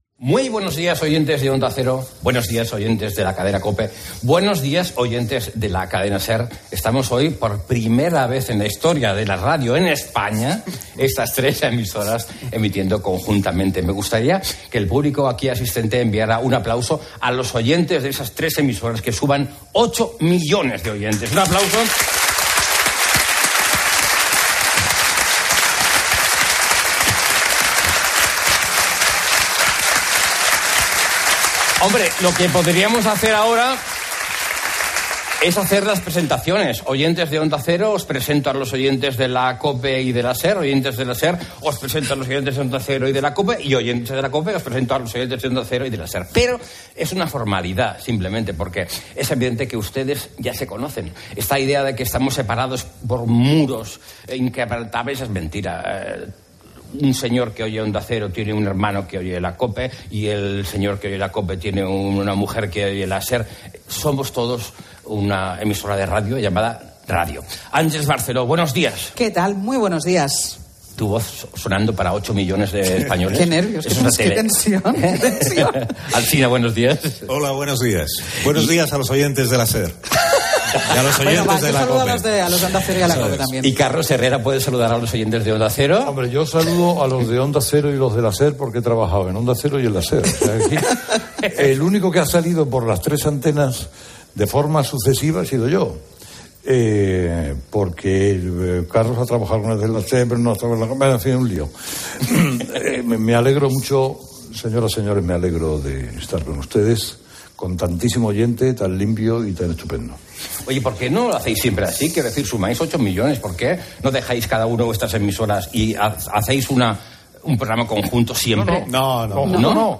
AUDIO: Herrera en COPE es el programa que más crece de la radio española. Está dirigido por el comunicador mejor valorado, Carlos Herrera....